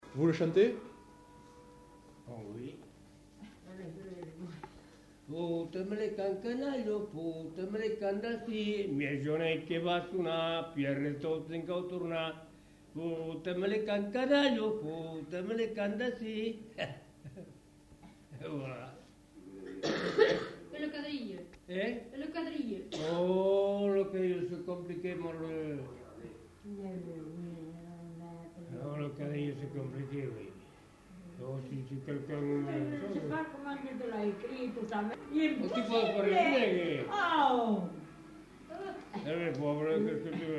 Aire culturelle : Savès
Lieu : Pavie
Genre : chant
Effectif : 1
Type de voix : voix d'homme
Production du son : chanté
Danse : rondeau